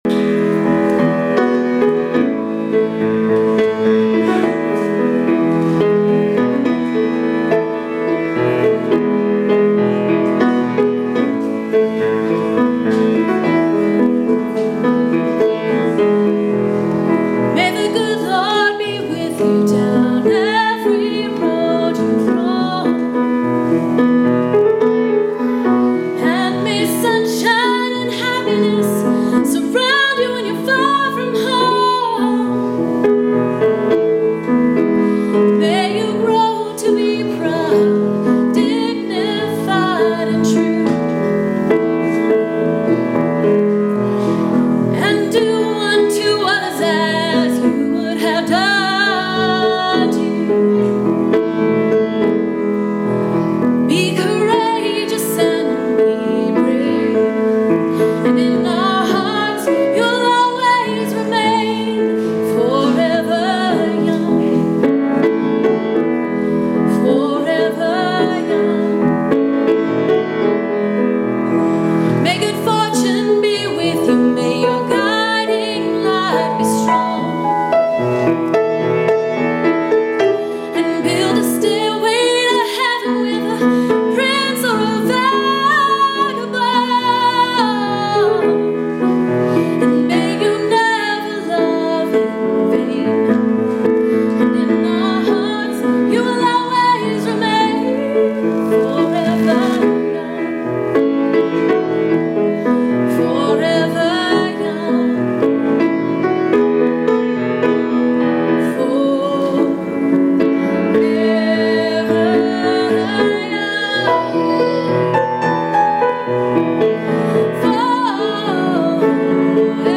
Forever Young - Musical Performance